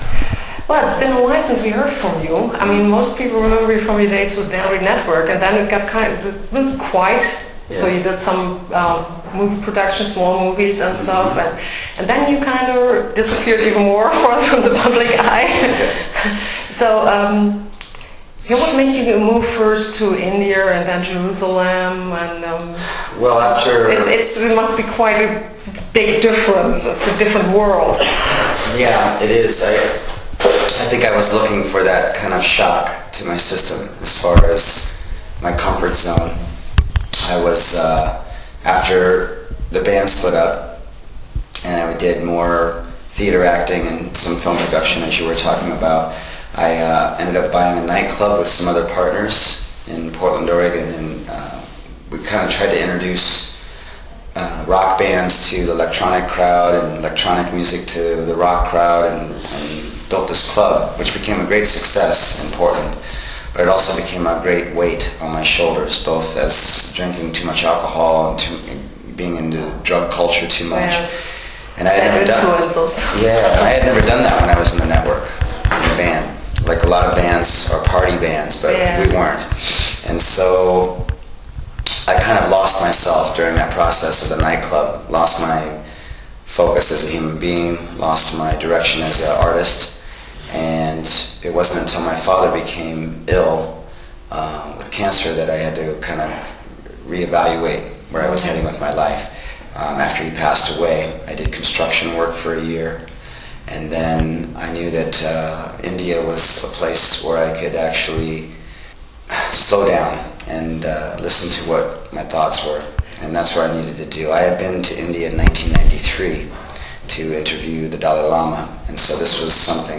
For quite awhile Dan Reed was vanished, then he started playing again solo shows and finally his new album is in stores. On a short European tour he stopped in Unna for a show and I took the chance to talk to Dan about the years between Dan Reed Network and his comeback as well as about the new album Comin' Up For Air.